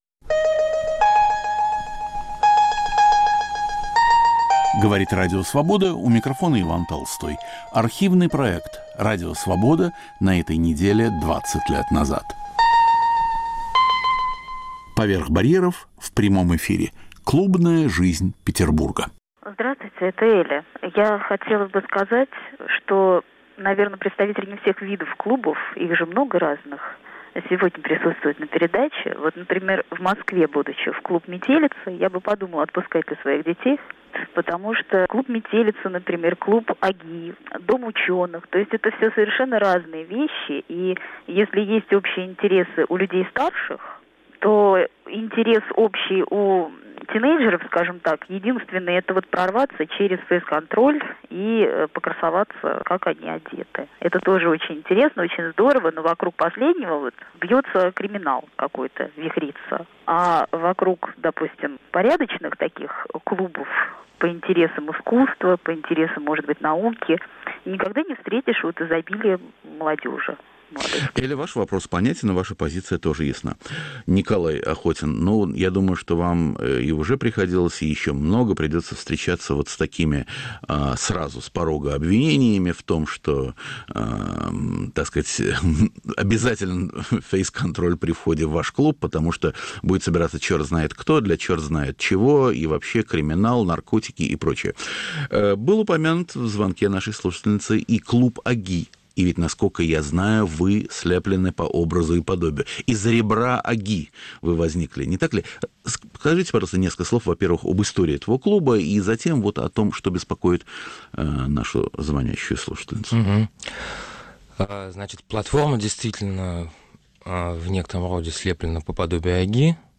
"Поверх барьеров" в прямом эфире. Клубная жизнь Петербурга
Автор и ведущий Иван Толстой.